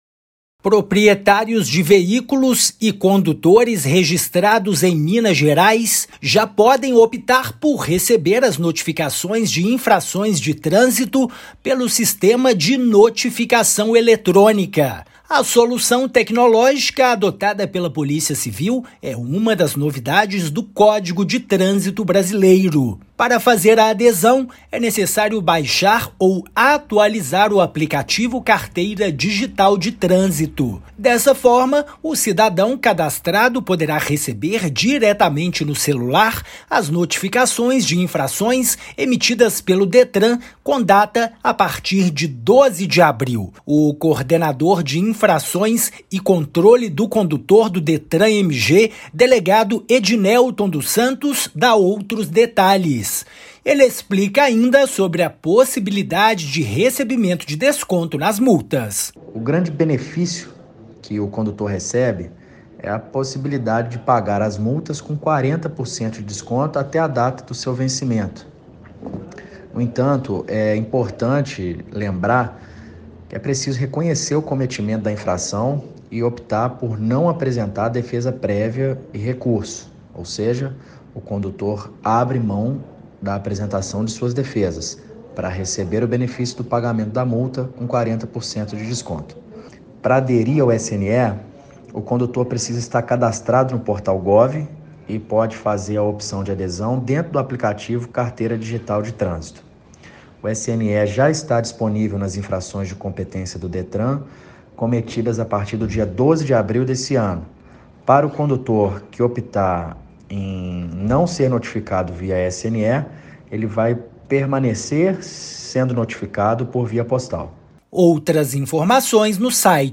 [RÁDIO] Novo sistema digital possibilita desconto de 40% em multas de trânsito
Com o SNE, proprietários de veículos e condutores têm a opção de receber as notificações de infração por meio de aplicativo, no celular. Ouça matéria de rádio.